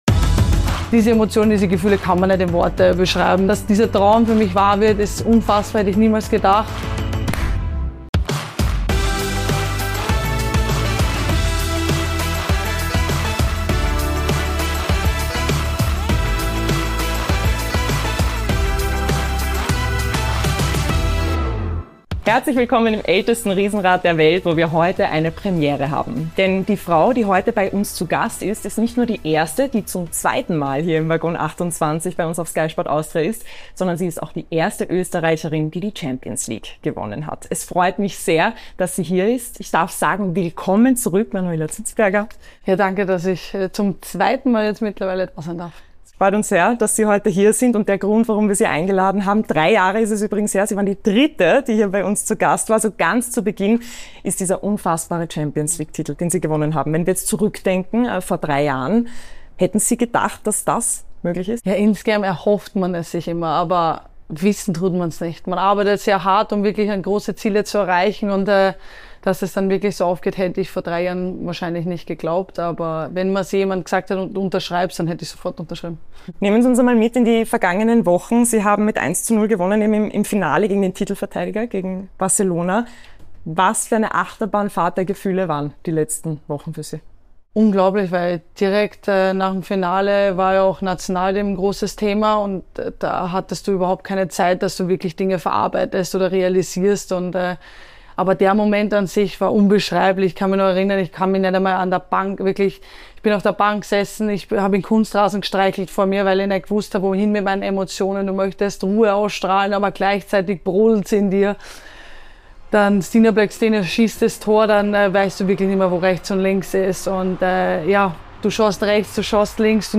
Talk Format